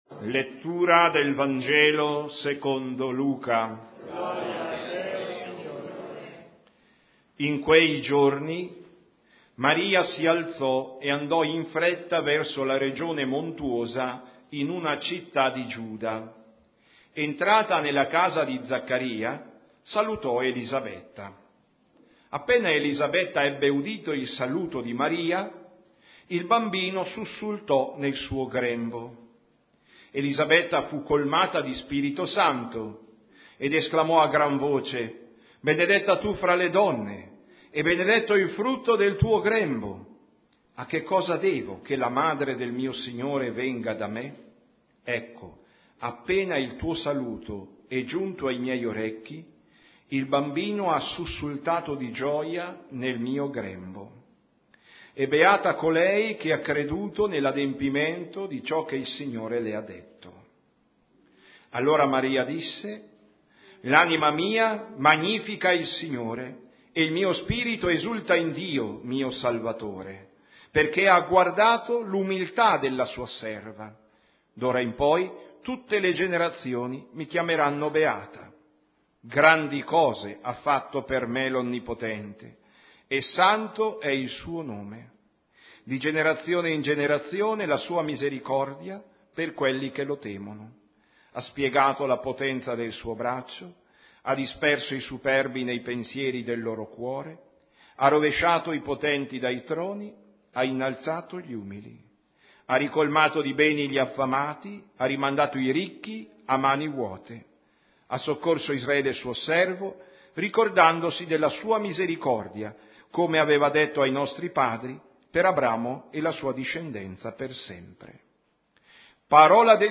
Omelia della Santa Messa del giorno